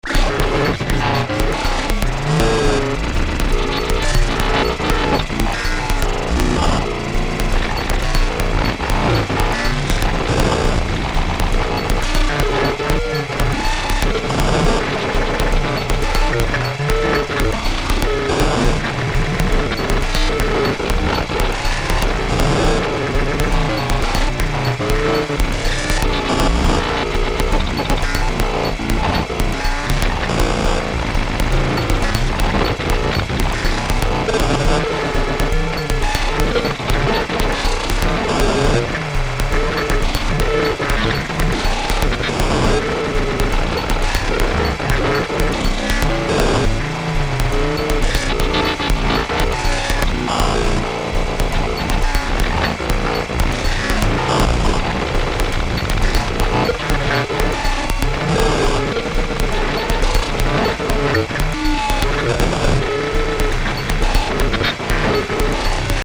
Algorithmic, Iterations